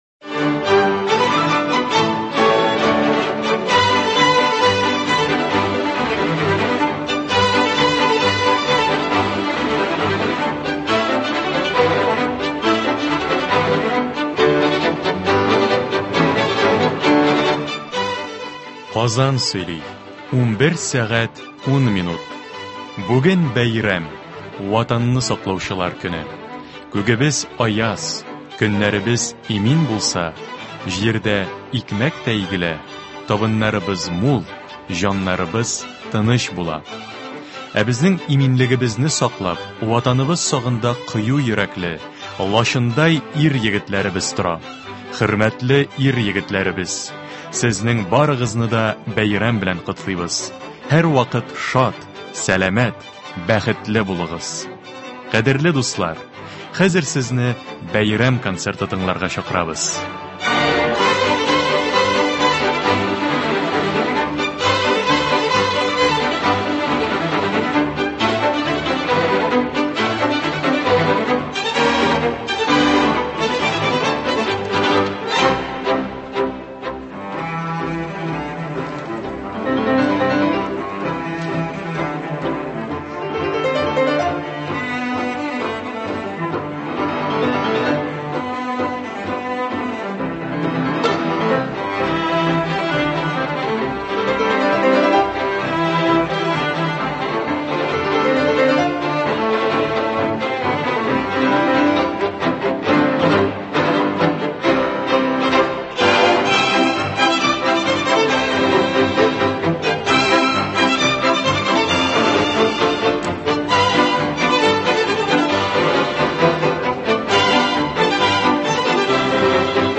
Бәйрәм концерты. 23 февраль.
Хөрмәтле радиотыңлаучылар, сезне Ватанны саклаучылар көне уңаеннан әзерләнгән бәйрәм концерты концерты тыңларга чакырабыз.